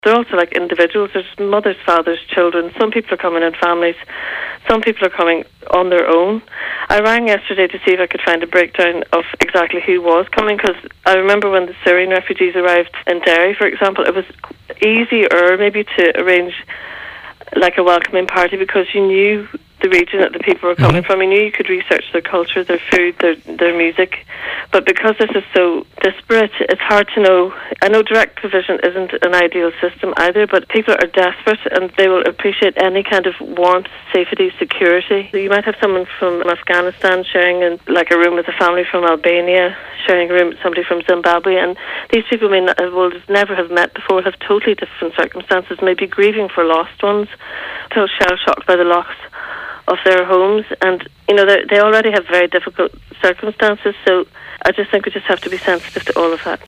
She told the Nine til Noon Show that those who are coming need support and security, and will be recovering from traumatic experiences…….